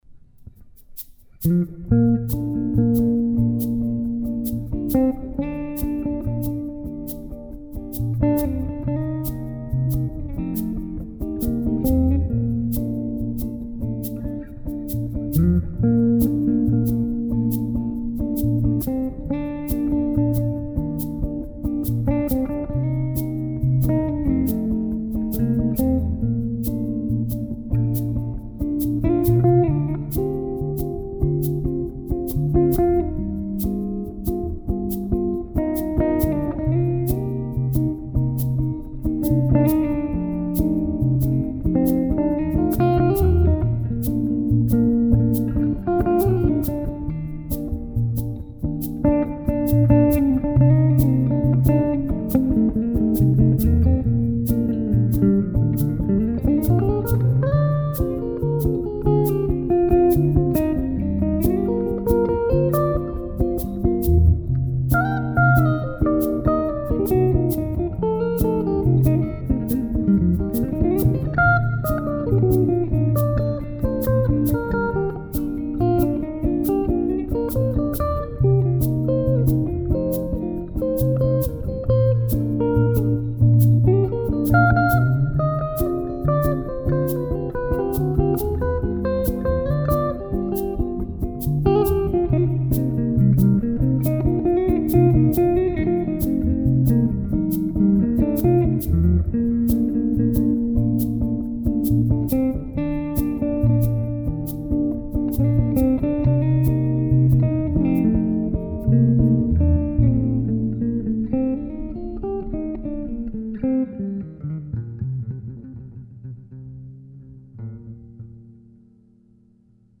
une bossa de Noël télécharger en mp3